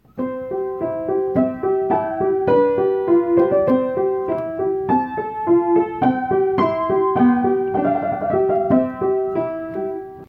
Here’s a recording of an old upright piano that hadn’t been tuned in years before tuning, and another recording of it after I had tuned it, so you can hear the difference:
Before (out-of-tune piano):
out-of-tune-piano.mp3